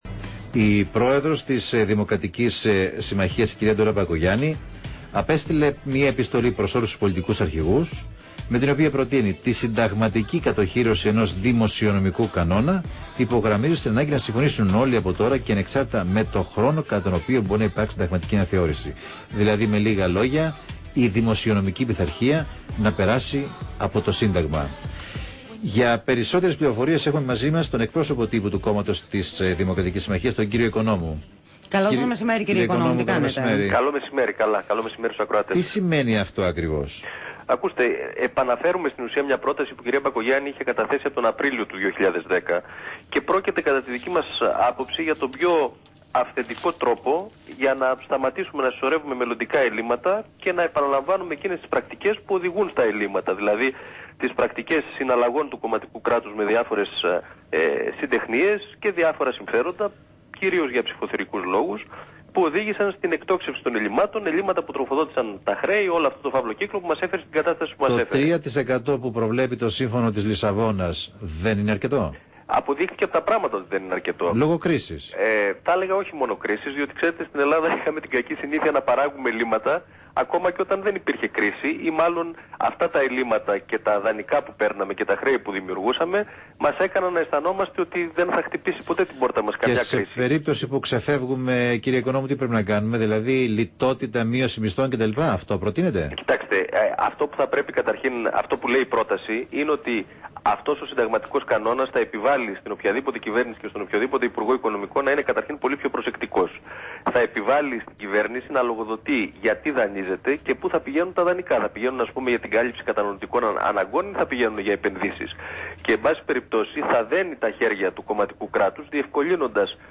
Συνέντευξη
στο ραδιόφωνο Real fm 97.8